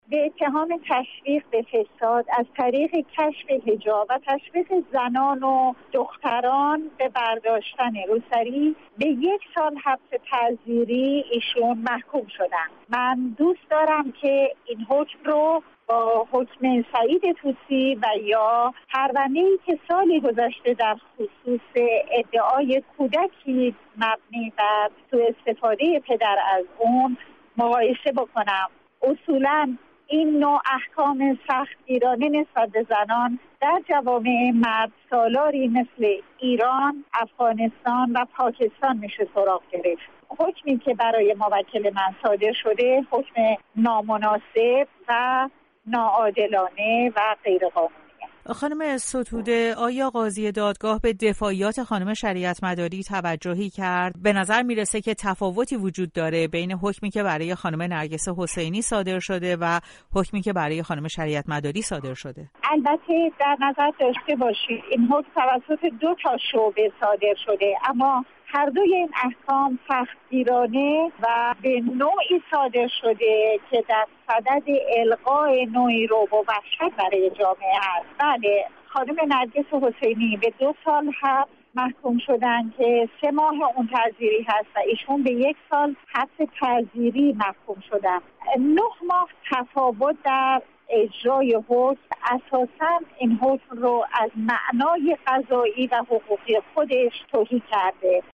گفت‌وگو با نسرین ستوده